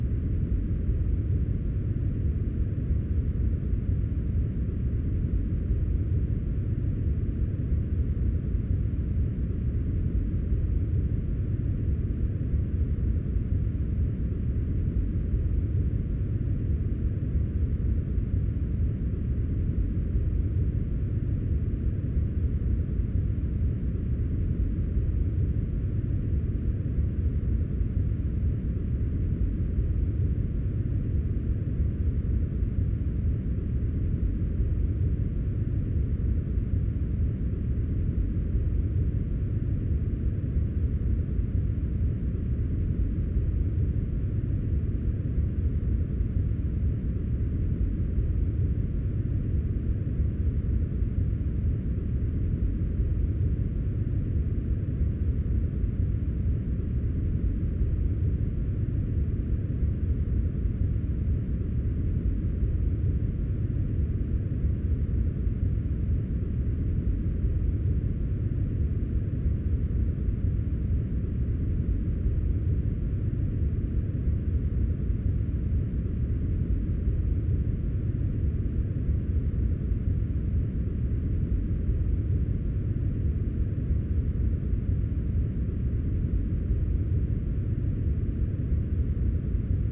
Глубокие низкие частоты создают успокаивающий эффект, помогая отвлечься от стресса.
Еще один оттенок коричневого шума